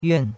yuan4.mp3